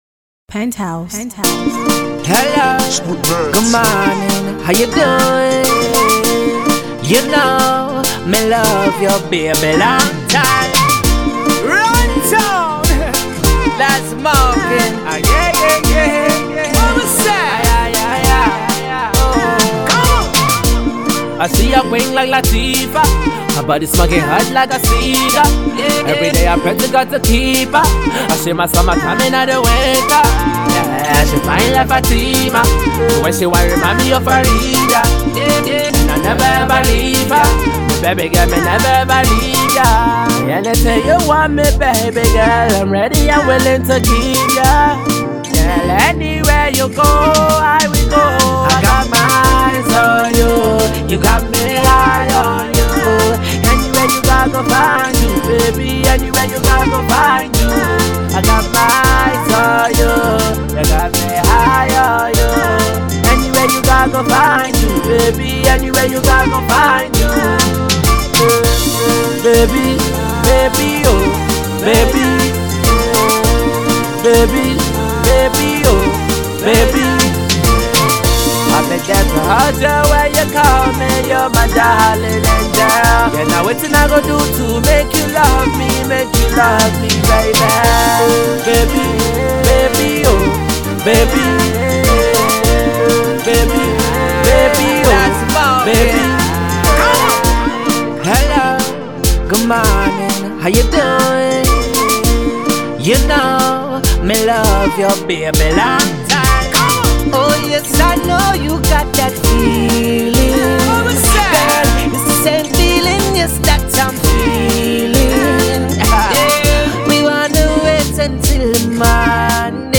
he features Jamaican-American crooner